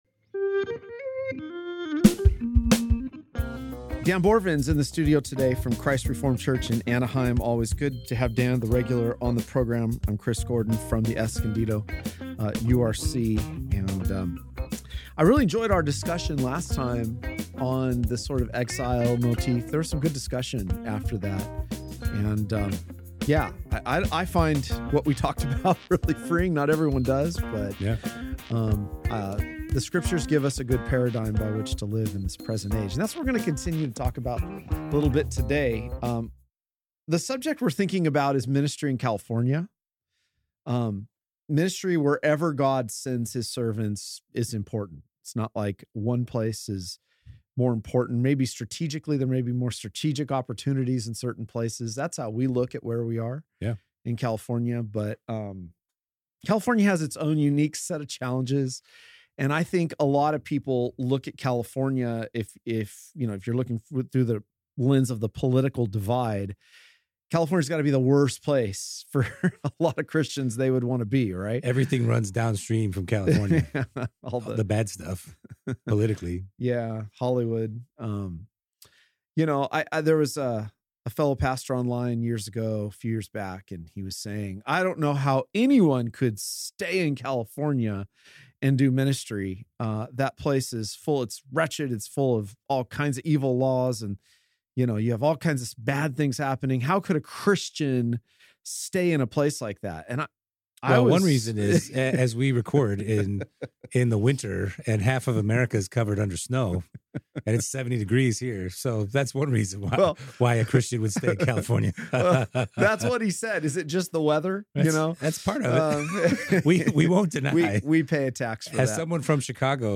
sit down in the AGR studio for a deeper look at what Paul meant and how it applies to their ministry in California, a place many see in an unfavorable light.